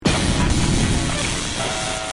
A Bite At Freddy's Jumpscare 2 Sound Button - Free Download & Play
Games Soundboard0 views